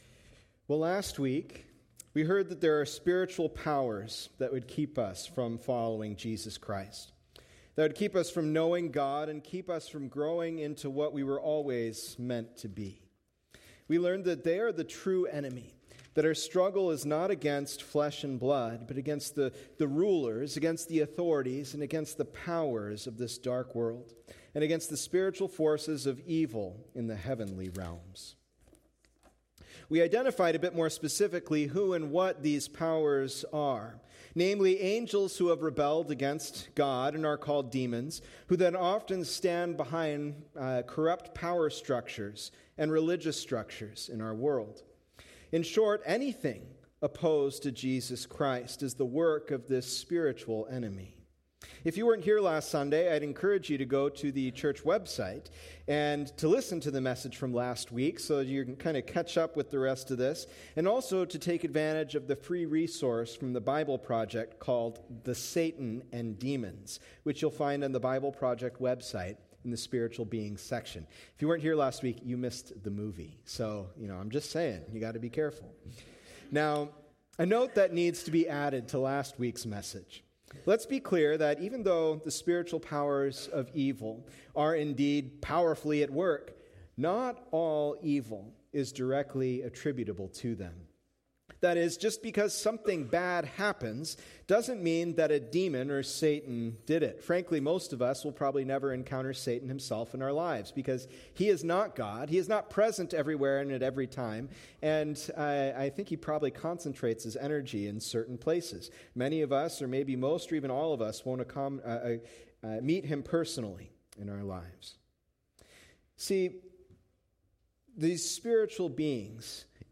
Sermons from Lemon Cove Community Church